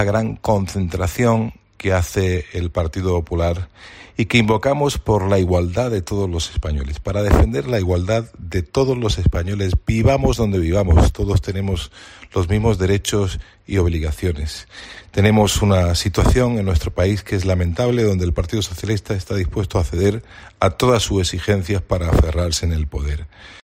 Carlos Rojas, diputado del PP